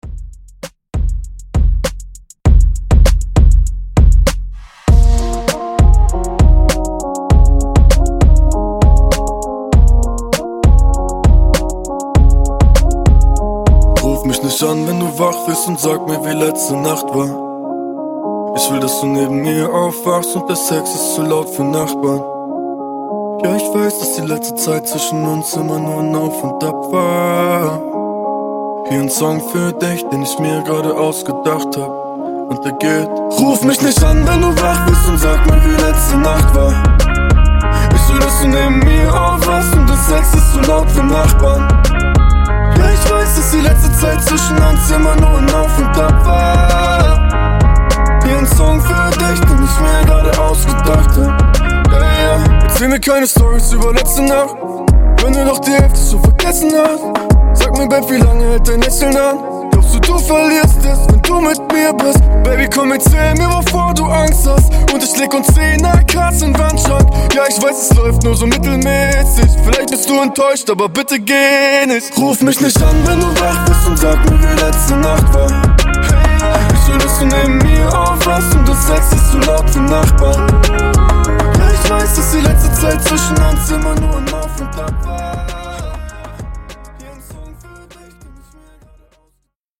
Genres: DRUM AND BASS , GERMAN MUSIC , RE-DRUM
Clean BPM: 82 Time